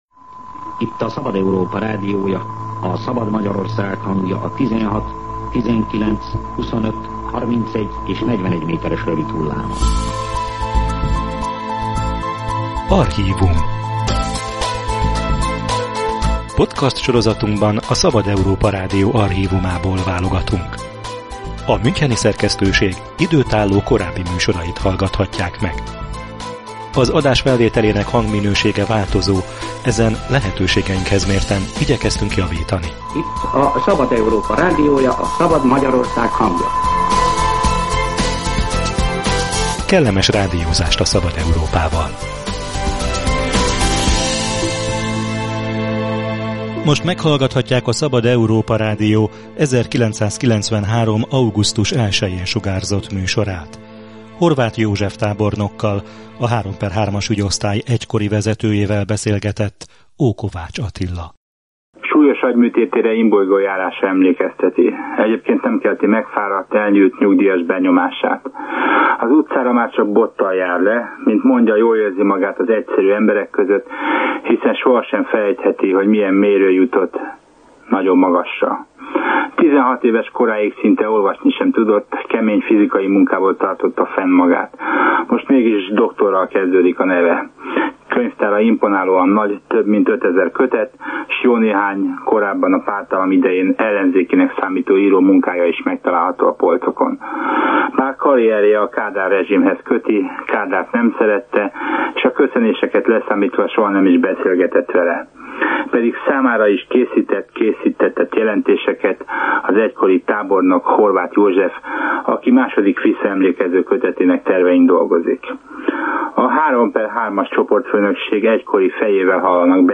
Kihallgatás, tanúverés, kikényszerített vallomások: ez ugrik be, ha a pártállami III/III-as ügyosztály nevét hallja az ember. Ebből semmi sem igaz – állította a Szabad Európa Rádió 1993. augusztus 1-jei műsorában Horváth József tábornok, az egység egykori vezetője, aki a működésükről beszélt.